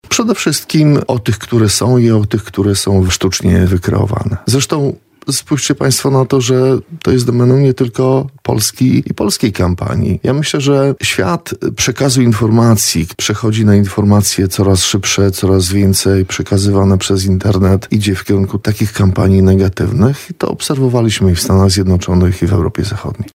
Po zwycięstwie Karola Nawrockiego liczę na twardą obronę polskich interesów na arenie międzynarodowej – powiedział w porannej rozmowie Radia Lublin senator Prawa i Sprawiedliwości Grzegorz Czelej.